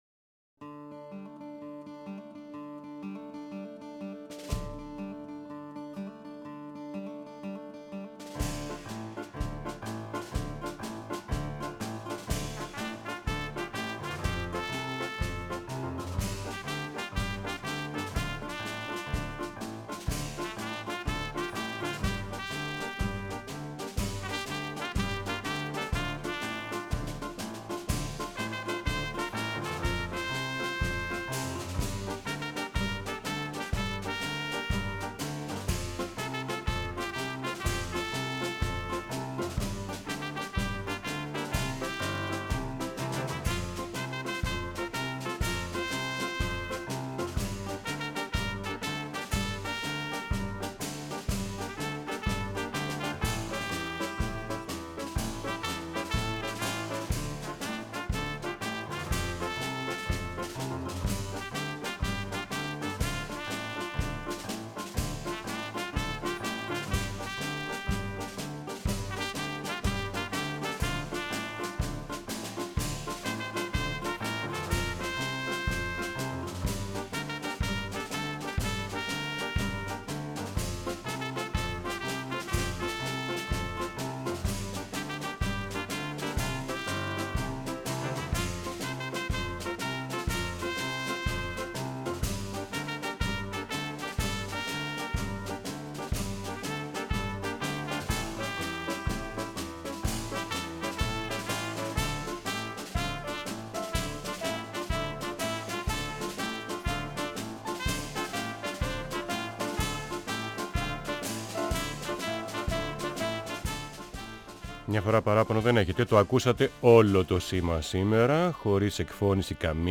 προσκεκλημένος τηλεφωνικά αφηγείται για τους ακροατές μια δική του ιστορία.